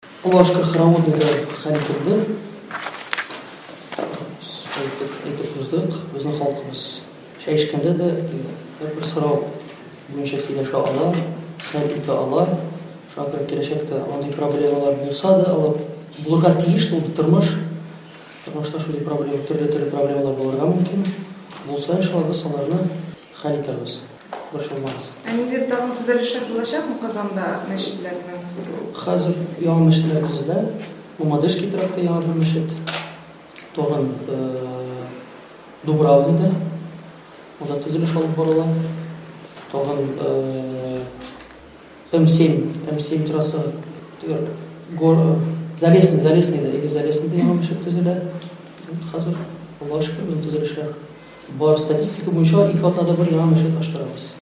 Ул бу хакта бүген журналистлар белән узган матбугат очрашуы вакытында әйтте.